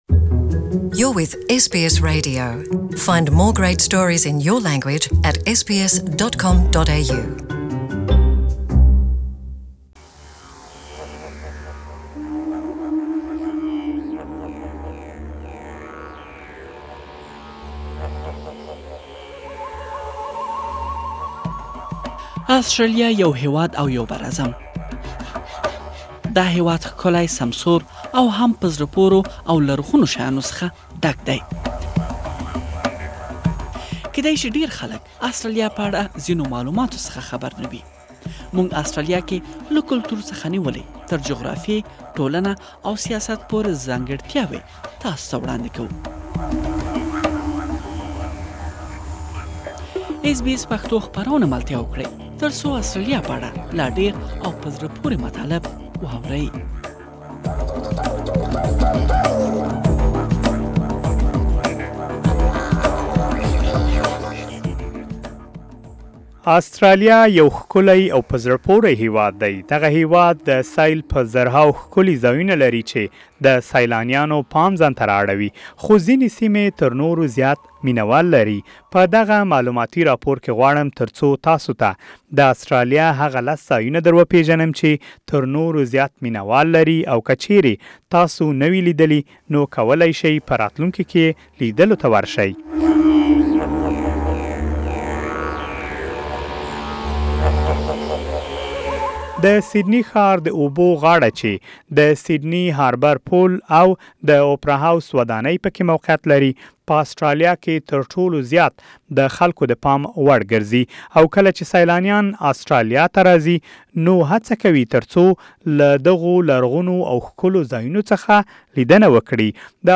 دغه هېواد د سیل په زرهاوو ښکلي ځایونه لري چې هرکال په میلیونهاوو کسان د دغو سیمو لیدلو ته ورځي. پدغه مالوماتي راپور کې تاسو کولای شئ، د استرالیا د سیل ۱۰ ښکلو ځایونو په اړه واورئ